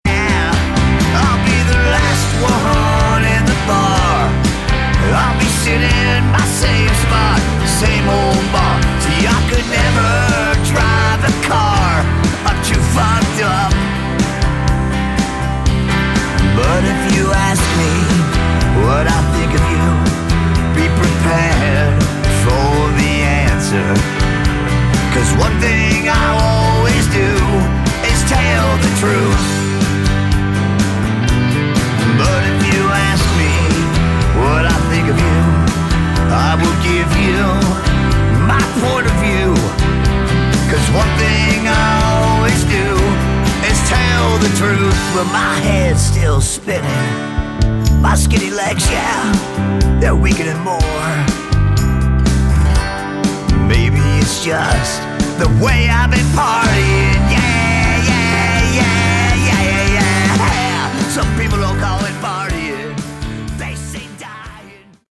Category: Sleaze Glam/Punk
lead vocals, guitars
bass, piano, mandolin, percussion, backing vocals
drums, backing vocals
guitar, ebow, backing vocals
acoustic guitar, electric steel, backing vocals